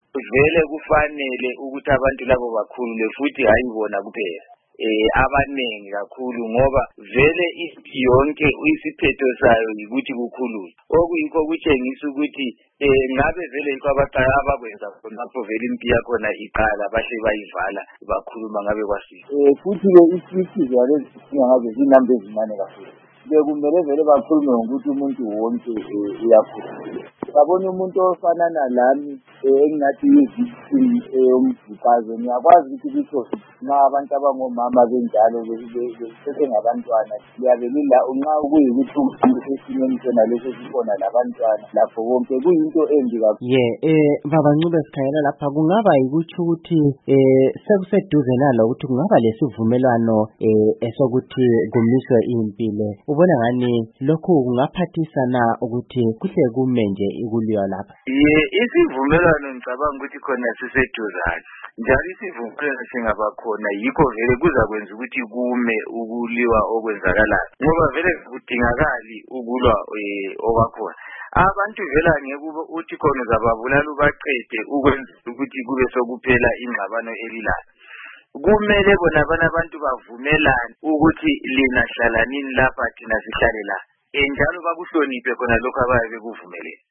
Ekhuluma leStudio7